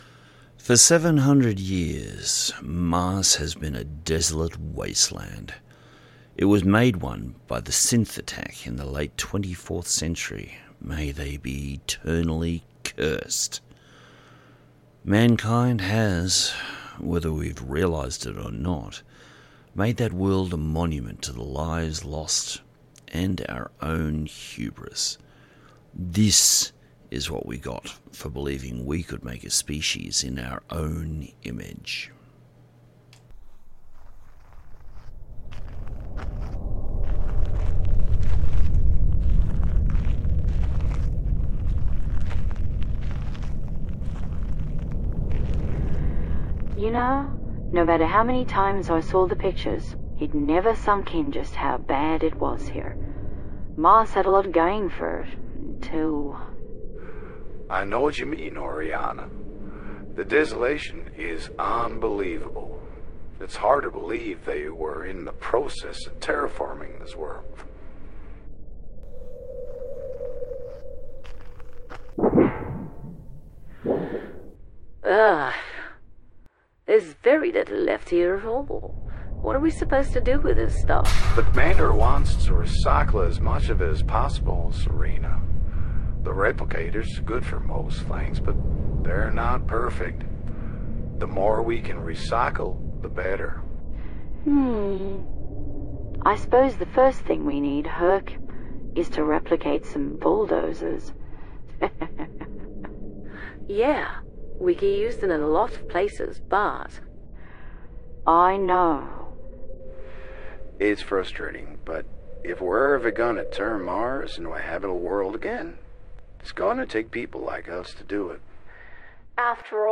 Audio Books/Drama